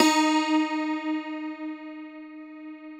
53q-pno11-D2.aif